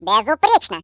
Russian localisation of default voice